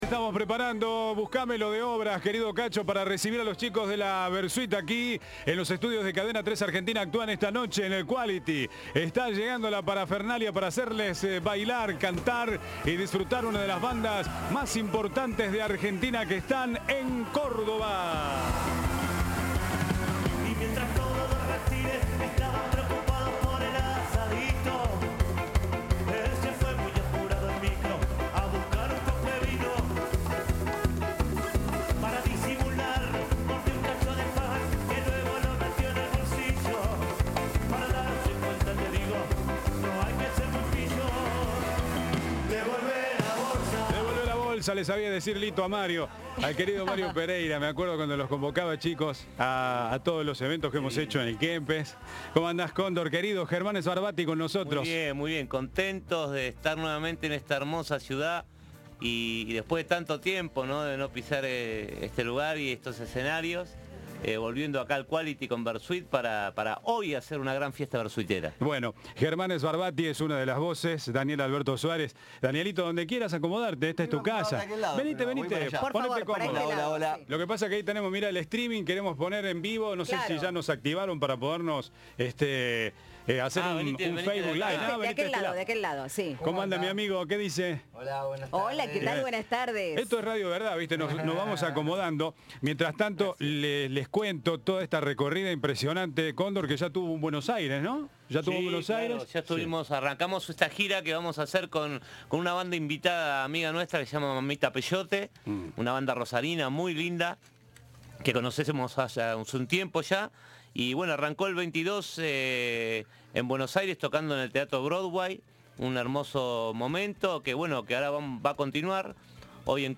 La banda actúa este jueves a la noche en Quality Espacio. Presenta su disco De la Cabeza 2, un material doble editado en el marco de los festejos del aniversario. Escuchá la entrevista.